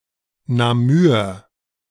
^ Adapted in German as [naˈmyːɐ̯]